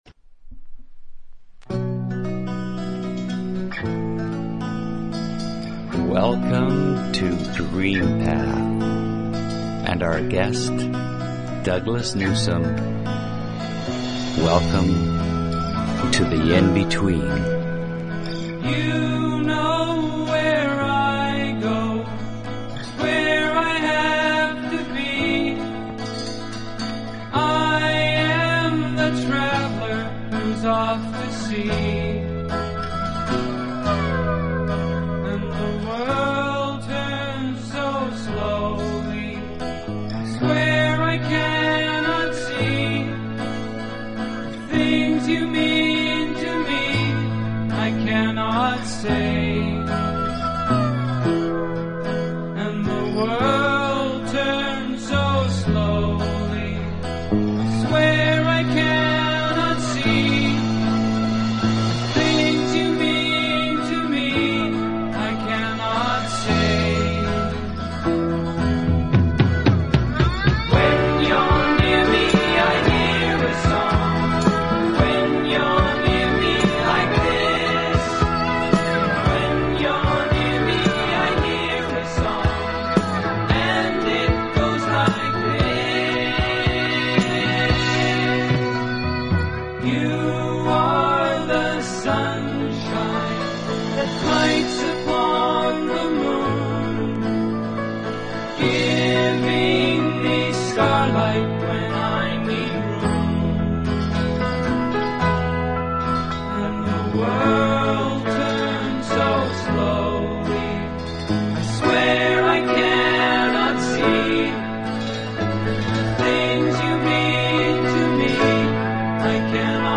Talk Show Episode, Audio Podcast, DreamPath and Courtesy of BBS Radio on , show guests , about , categorized as
Comedy, music, and a continuous weaving of interviews and story telling (DreamPath style) covering numerous alternative thought topics.